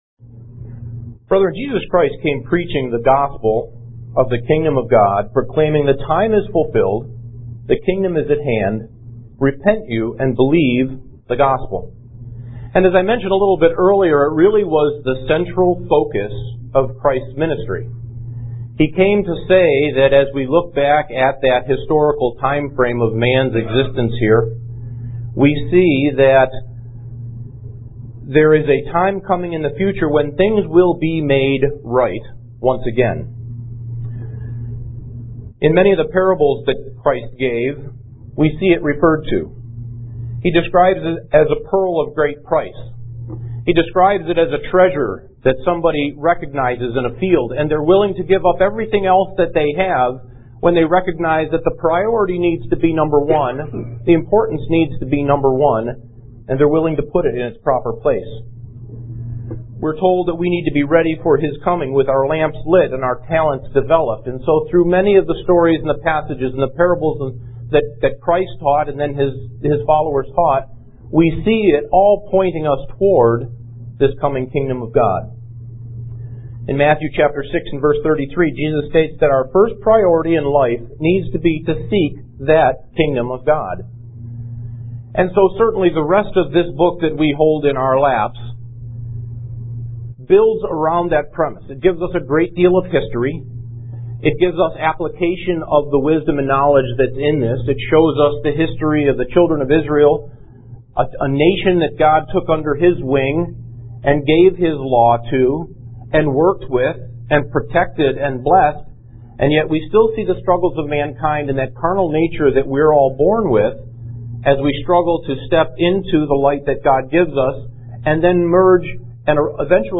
Kingdom of God Seminar 4: Part 1 UCG Sermon Studying the bible?